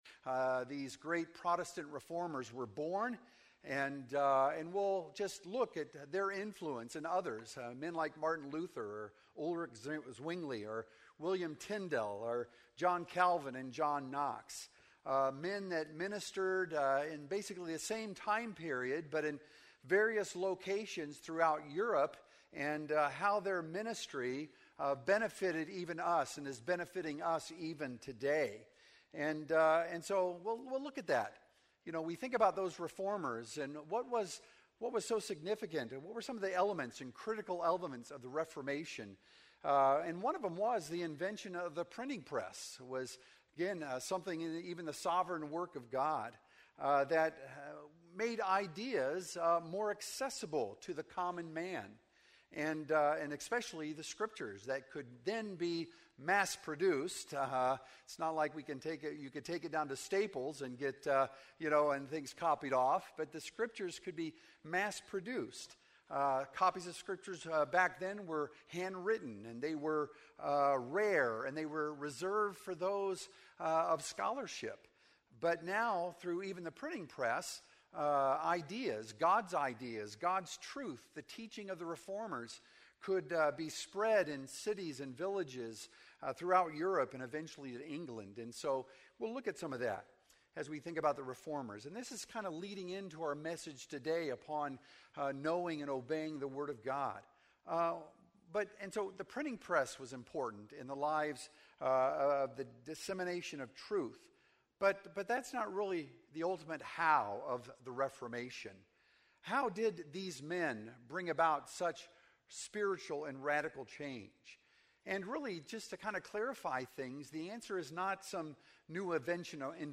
Sermons - Emmanuel Baptist Church
Sunday Morning Worship